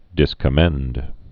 (dĭskə-mĕnd)